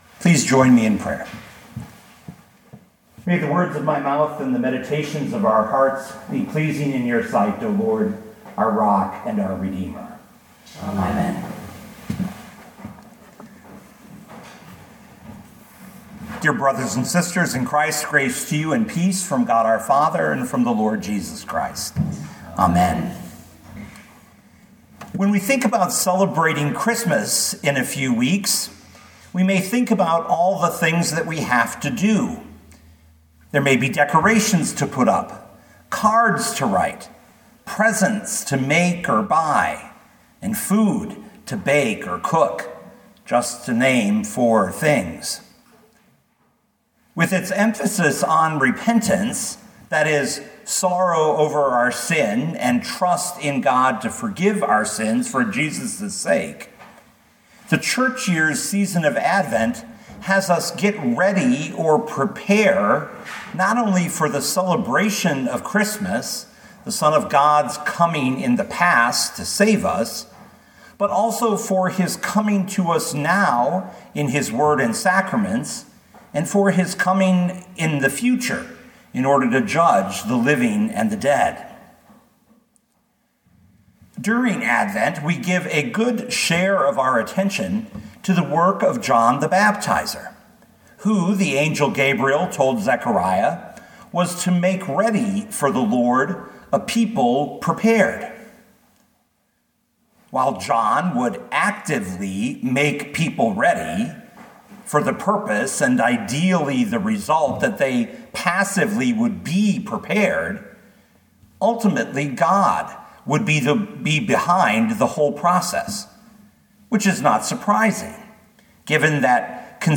Jeremiah 51:15-19 Listen to the sermon with the player below, or, download the audio.